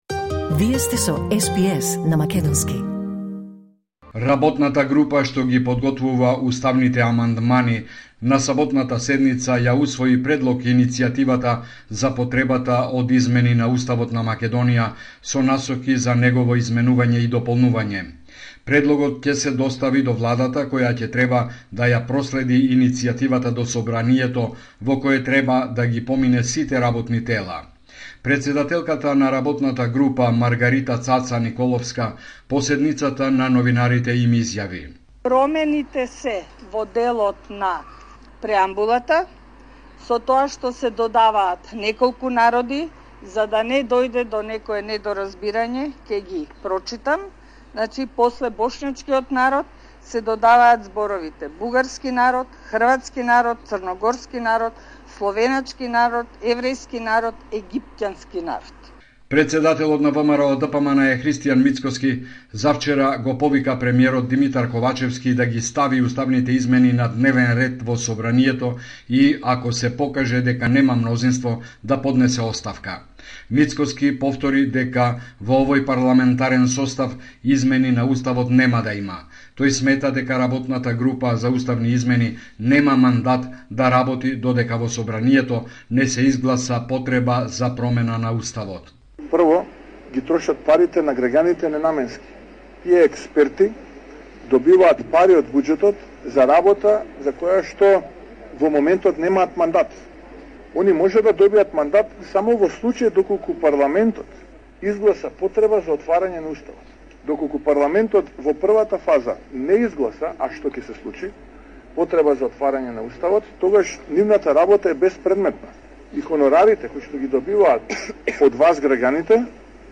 Извештај од Македонија 29 мај 2023
Homeland Report in Macedonian 29 May 2023